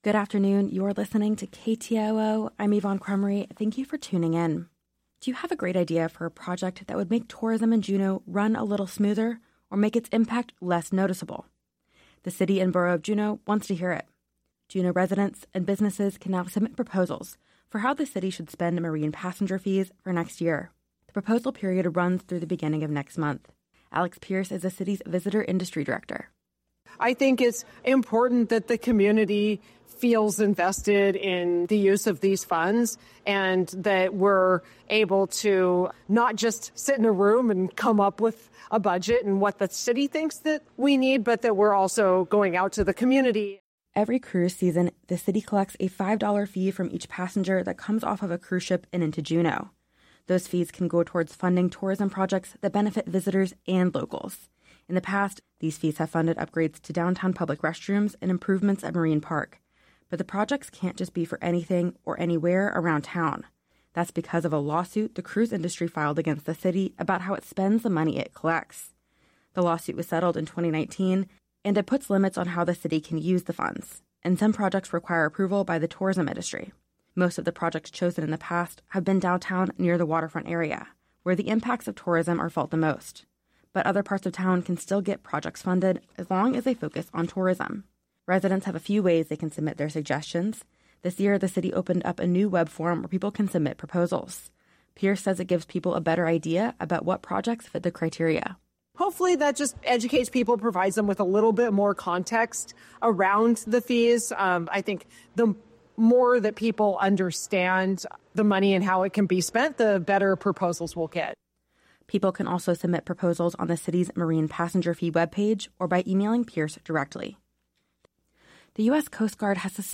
Newscast – Tuesday, Dec. 3, 2024